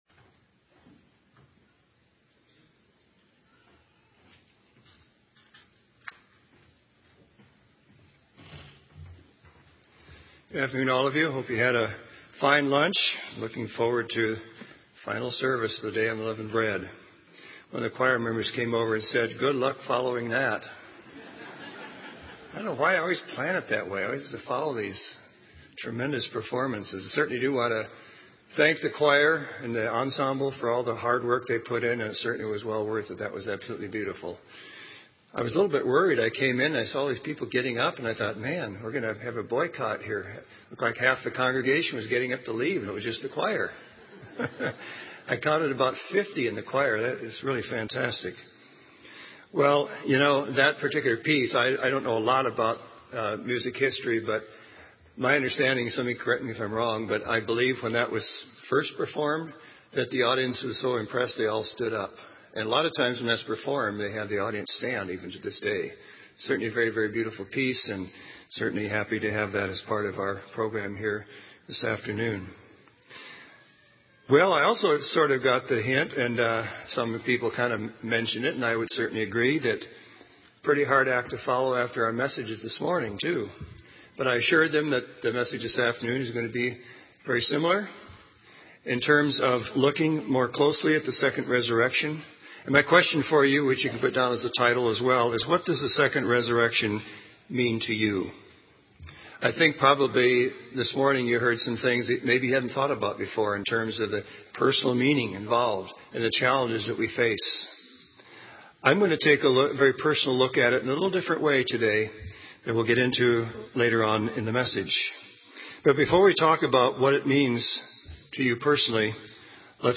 This sermon was given at the Bend, Oregon 2010 Feast site.